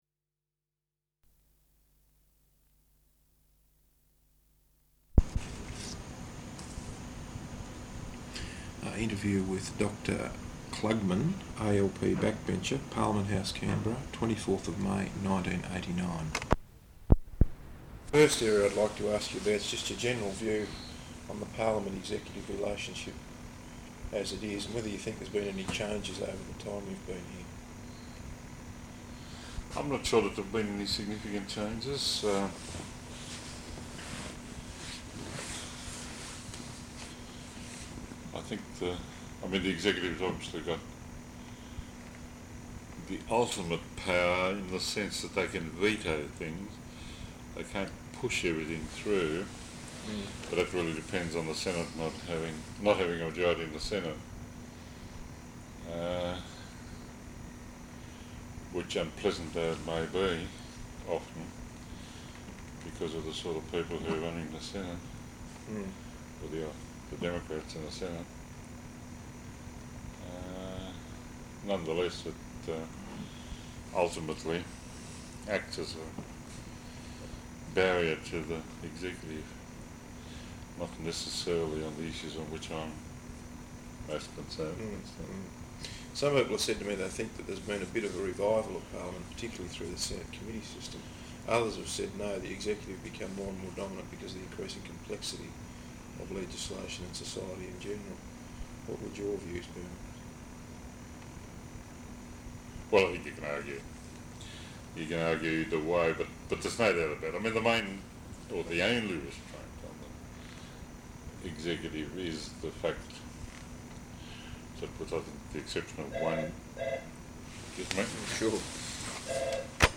Interview with Dr Dick Krugman, ALP Backbencher, at Parliament House Canberra, 24th May 1989.